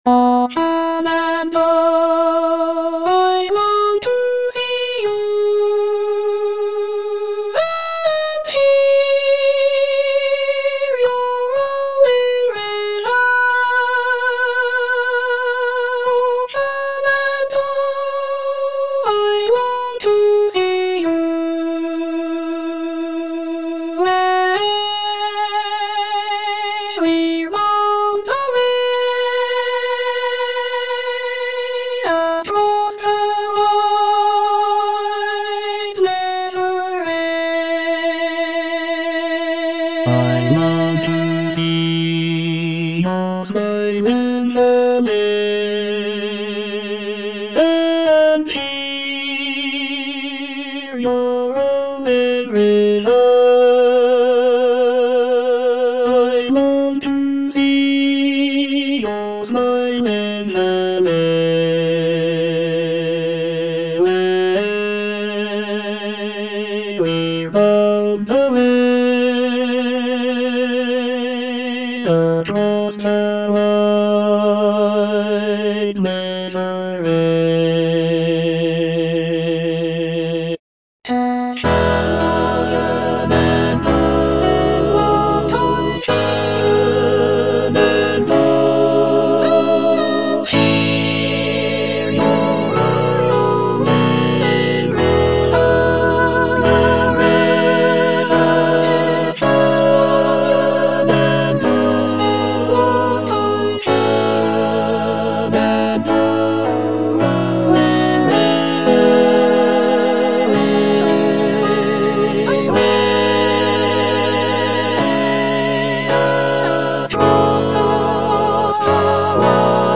All voices
traditional American folk song